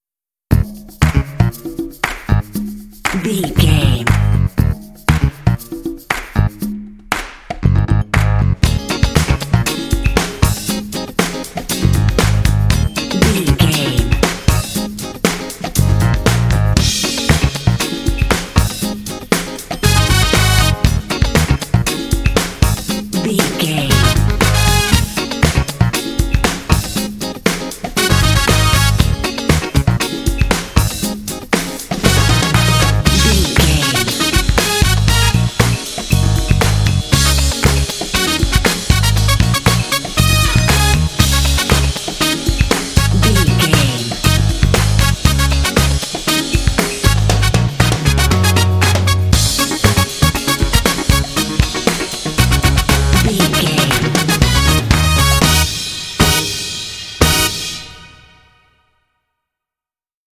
This epic, funky track is great for action games
Epic / Action
Aeolian/Minor
E♭
groovy
funky
energetic
driving
bouncy
bass guitar
saxophone
electric guitar
brass
percussion
drums